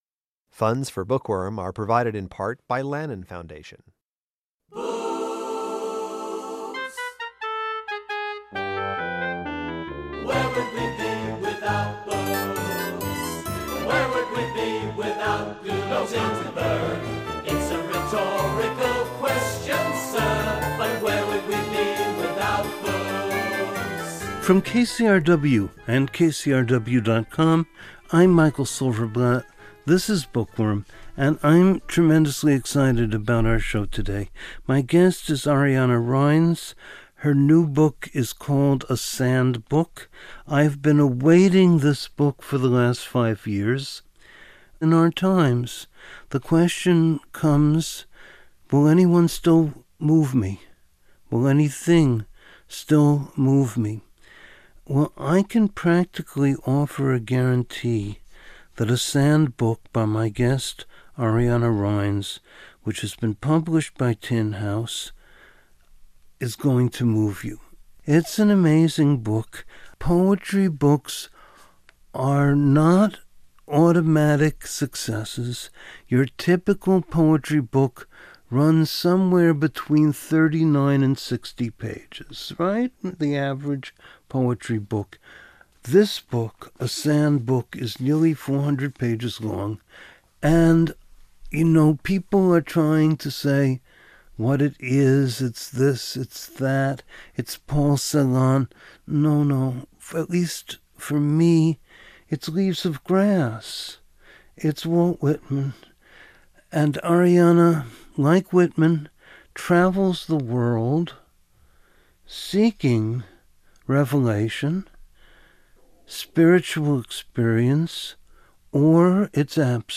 Ariana Reines speaks of books that go beyond themselves and stay with the reader; she wrote one, and reads three poems from it.